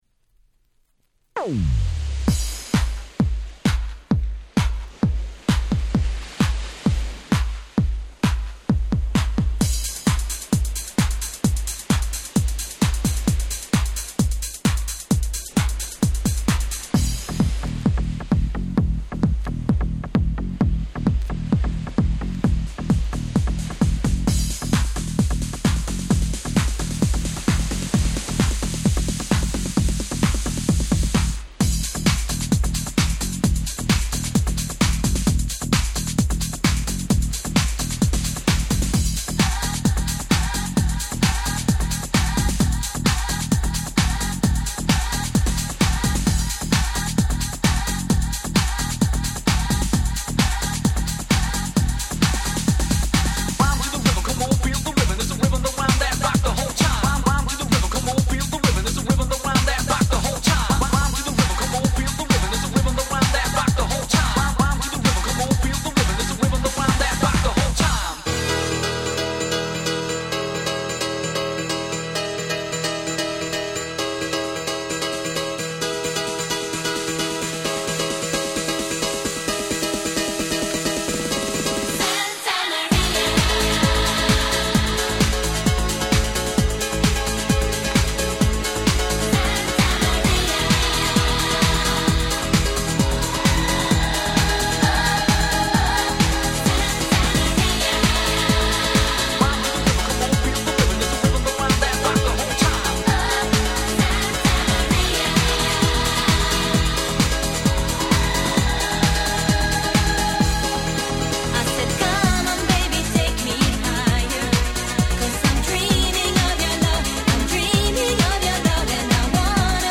95' Super Hit House/Dance Pop !!
「サンタマリア〜」のキャッチーなサビでここ日本でも当時はバカ流行りしました！